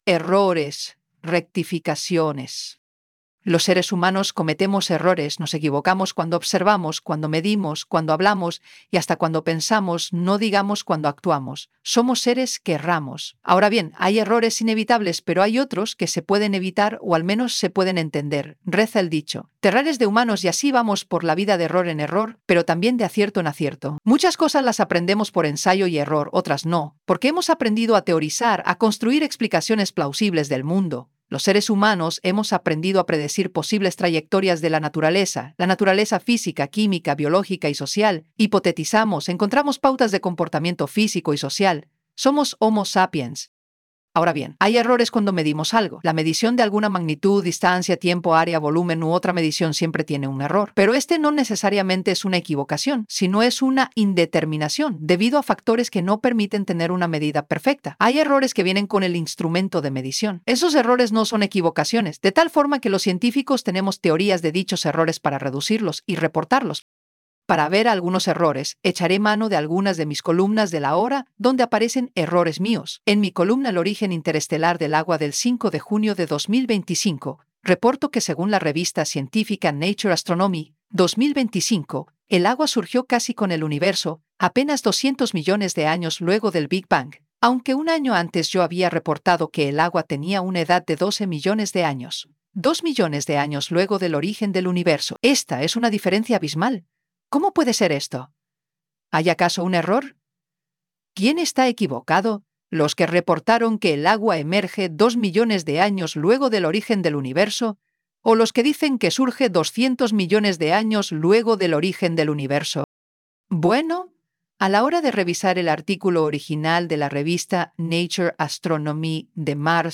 PlayAI_Errores_rectificaciones.wav